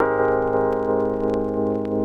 keys_89.wav